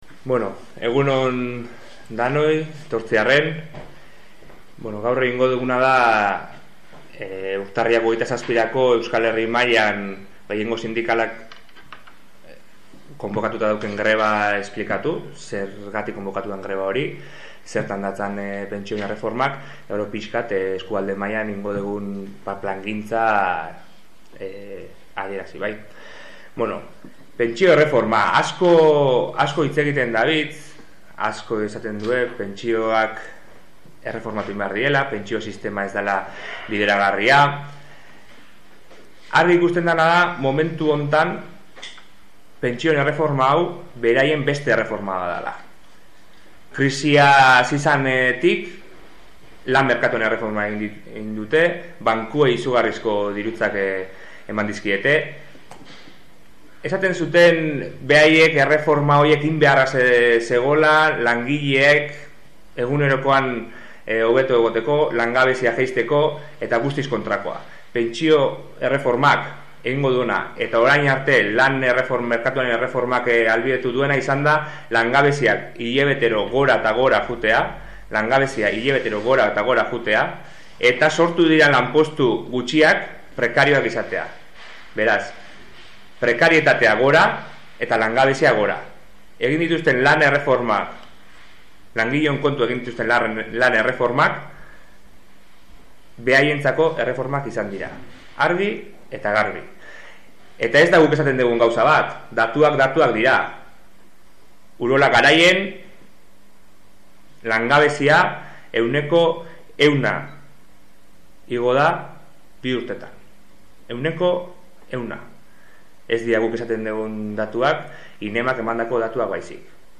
Greba deialdiaren nondik norakoak azaltzeko prentsaurrekoa eman dute gaur goizean Goierri Garaiko ELA eta LAB sindikatuek.